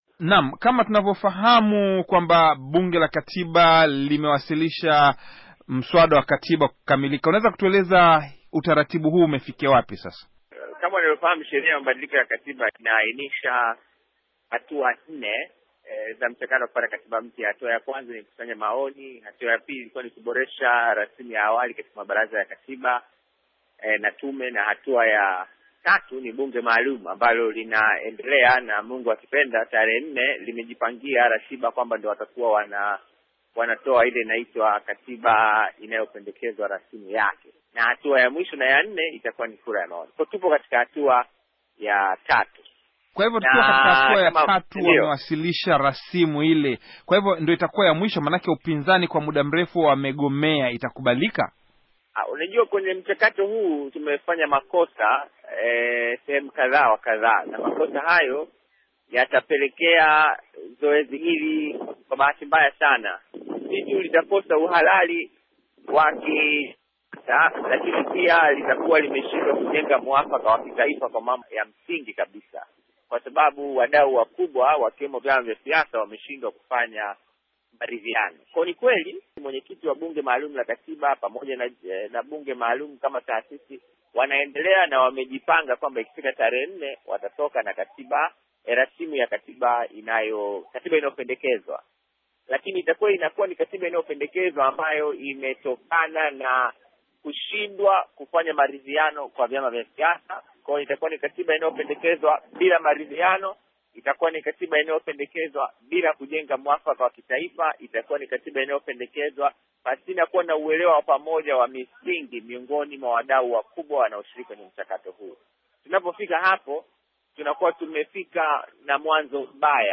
Mahojiano na Humphery Polepole